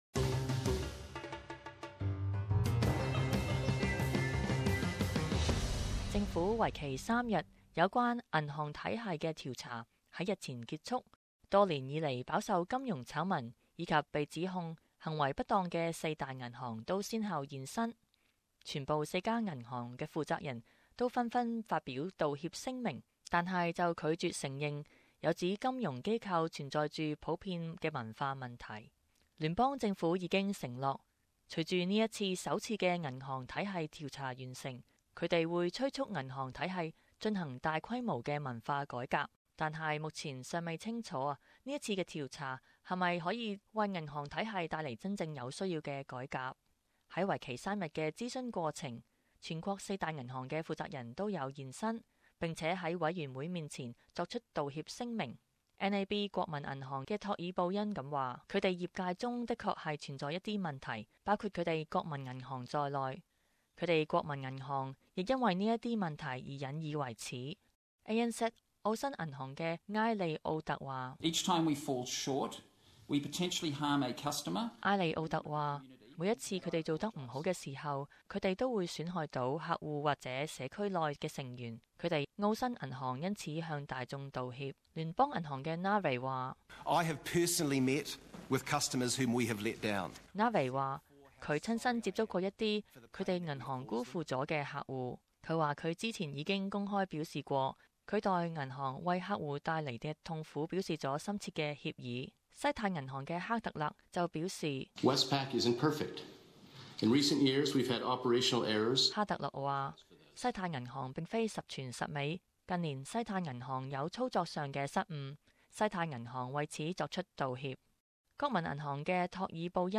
【时事报导】四大银行总裁道歉